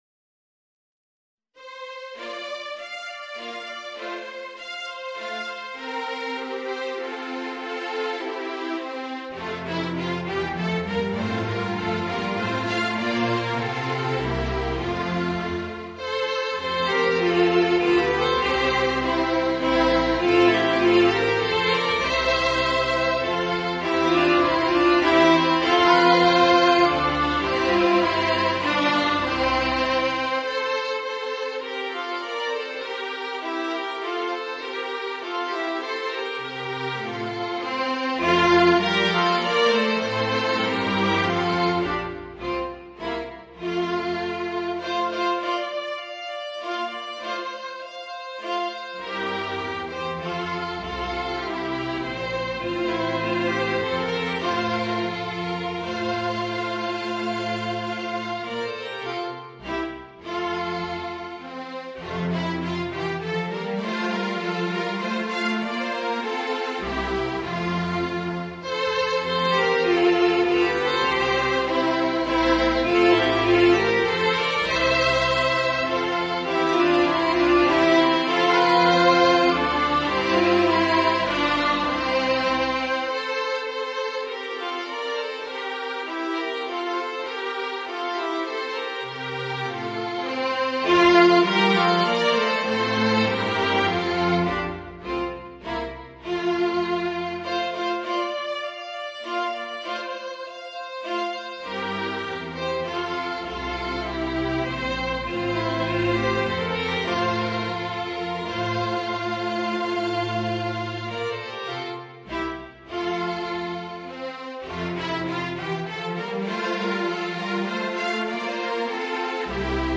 The MP3 was recorded with NotePerformer.
Violin Duet with Piano accompaniment: